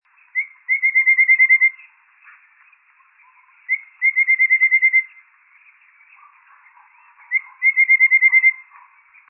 紅頭穗鶥 Stachyris ruficeps praecognita
高雄市 鼓山區 柴山
錄音環境 雜木林
行為描述 鳴唱
標籤/關鍵字 鳥